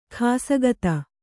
♪ khāsagata